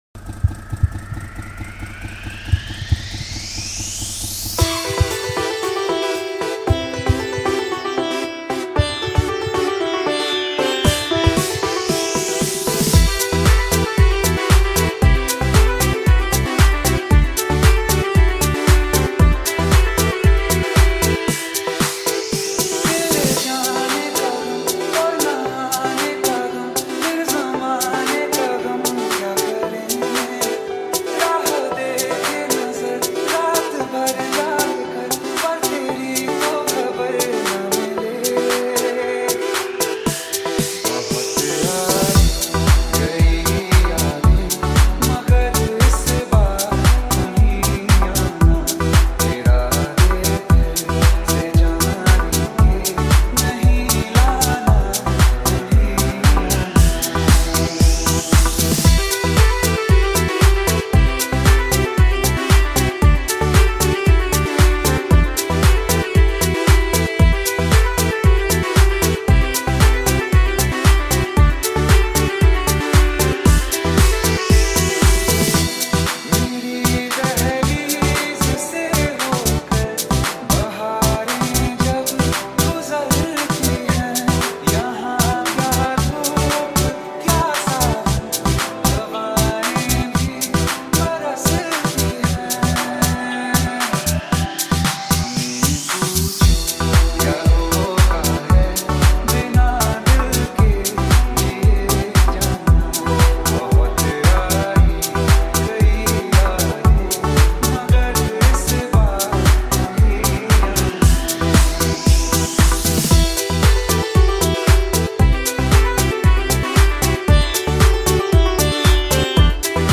Sawanatha Remix New Song